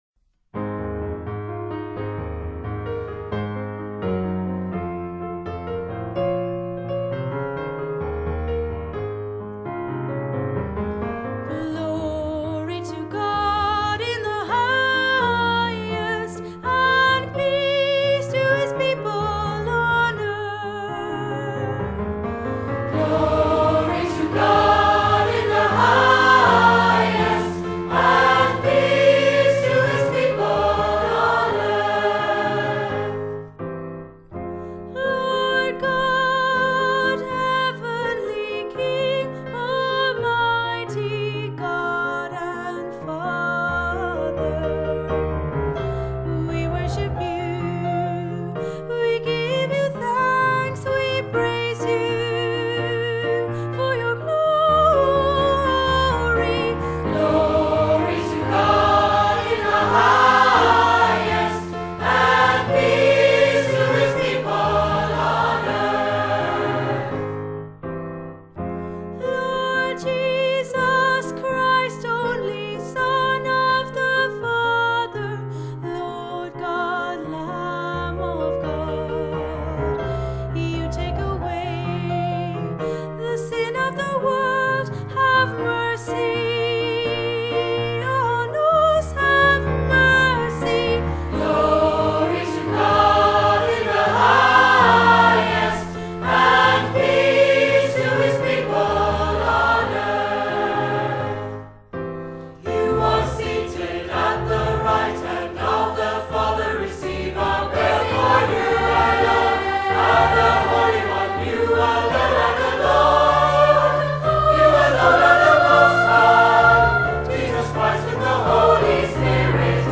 Gospel Choir